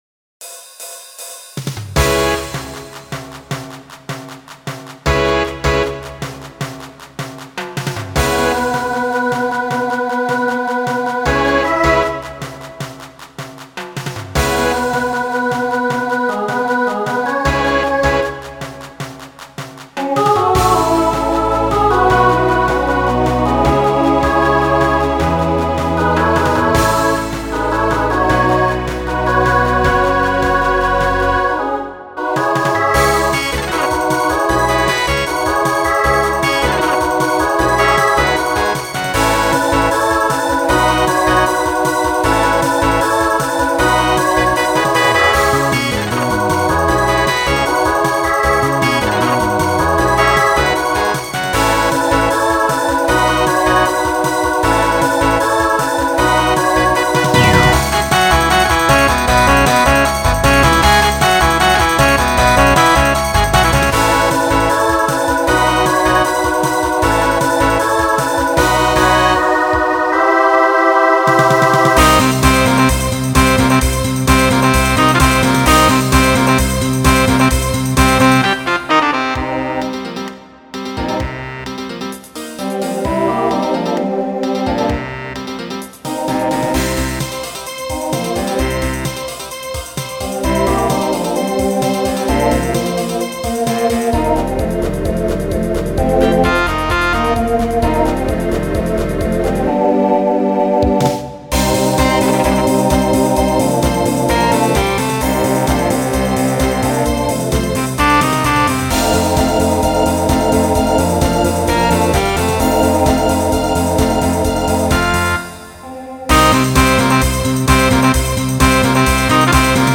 SSA/TTB
Voicing Mixed Instrumental combo
Pop/Dance